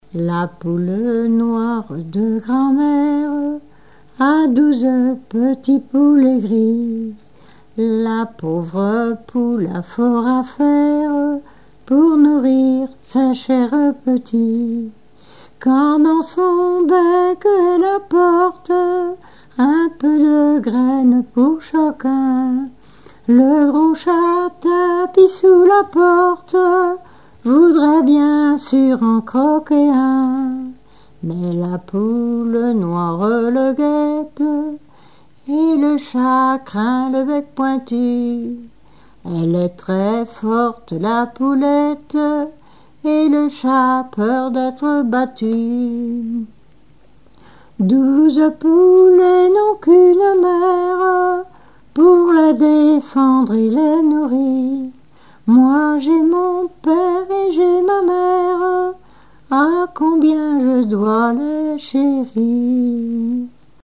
Genre strophique
Catégorie Pièce musicale inédite